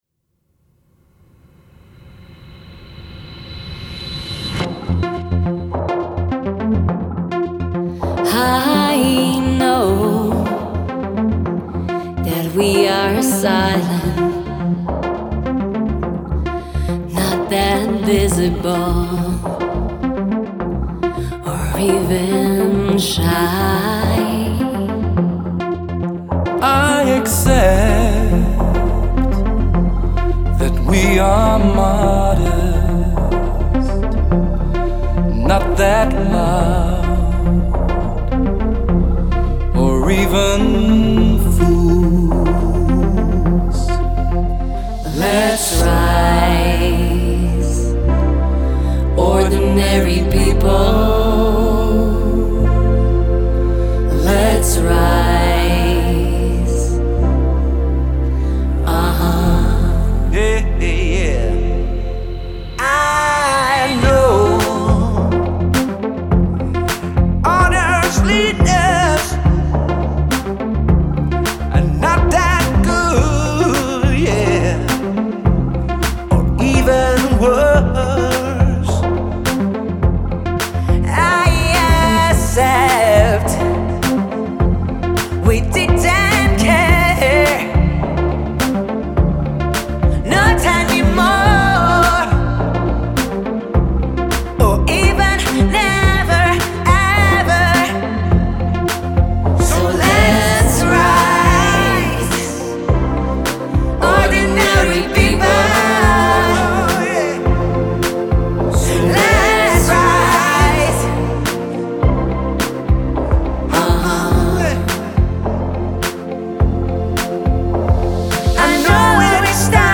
riječki rock band
vokal
klavijature
gitare
bubnjevi